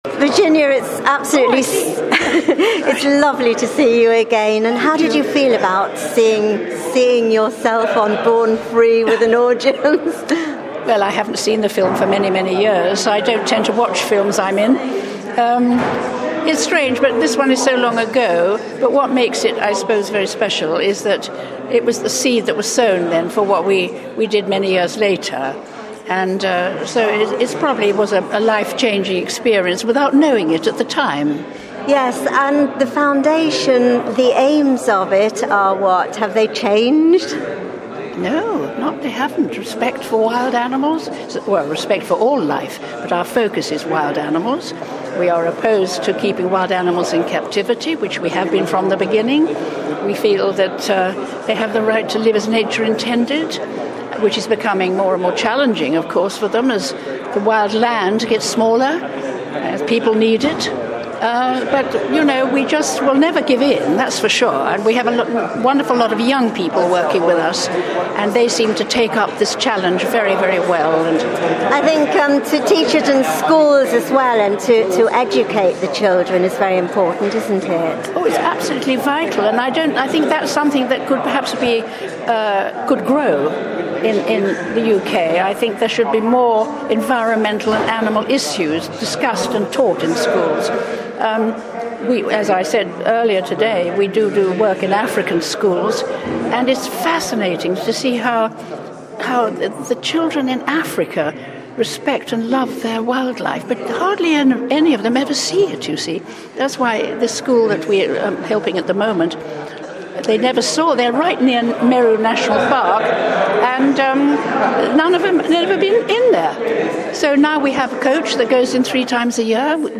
Virginia McKenna told Radio Jackie how the film inspired her love of animals out of which the Foundation was born.